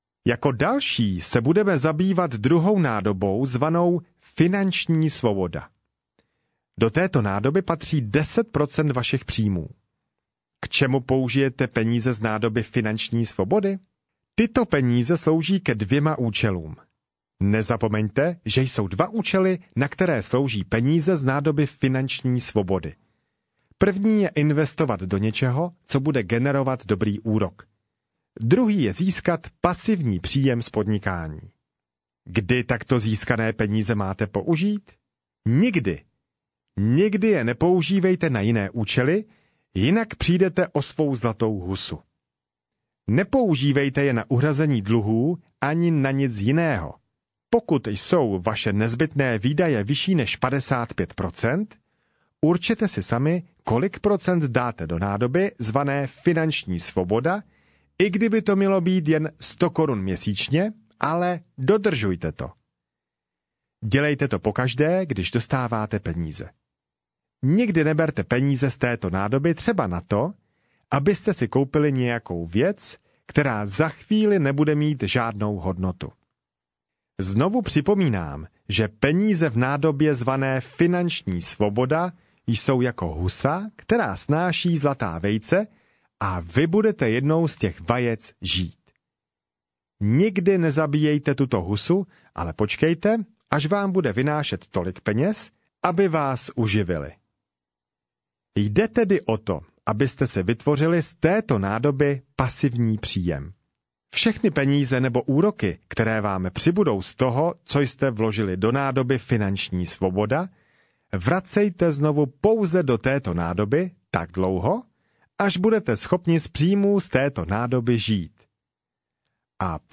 Systém šesti nádob audiokniha
Ukázka z knihy